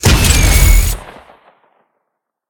combat / weapons / rocket / fire1.ogg